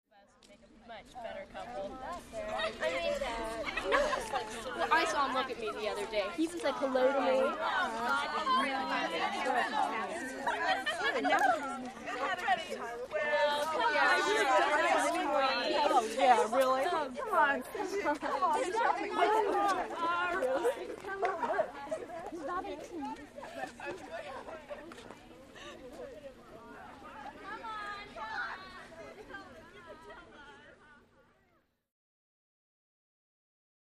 Teenage Girls Walking By, W Talking Gossip. Right To Left.